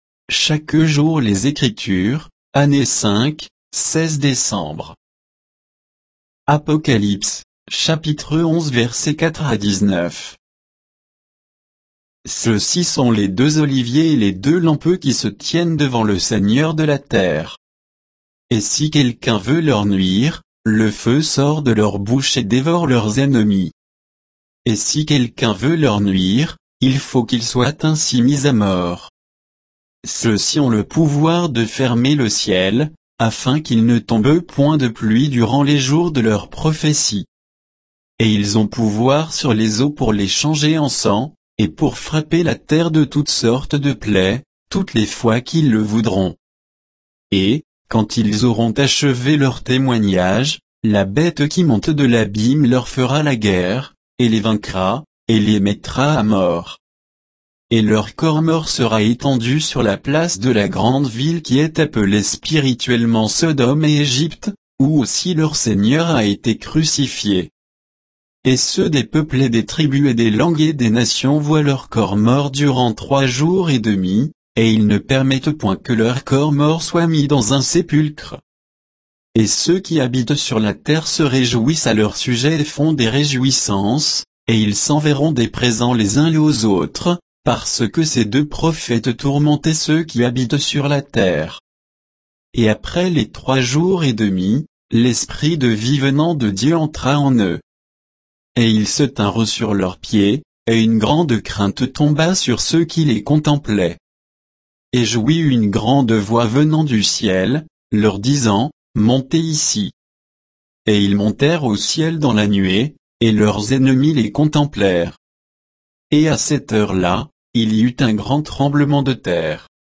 Méditation quoditienne de Chaque jour les Écritures sur Apocalypse 11